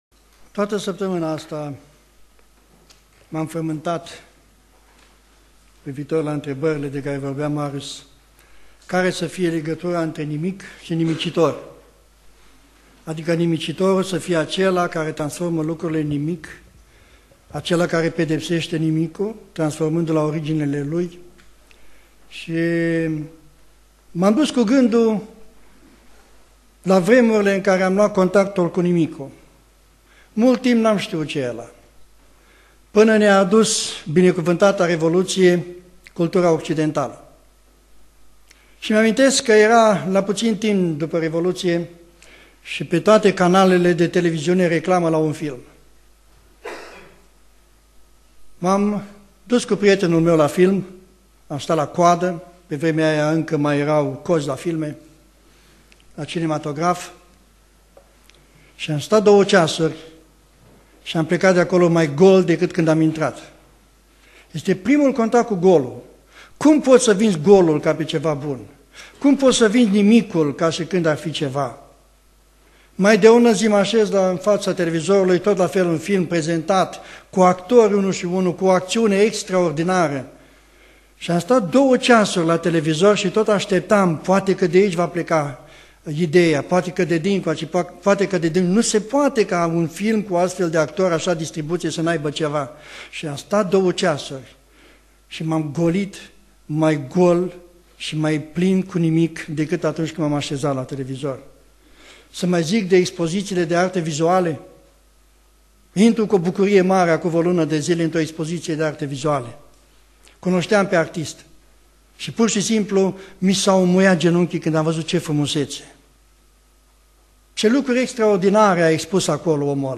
Predica Aplicatie - Ieremia cap. 4-5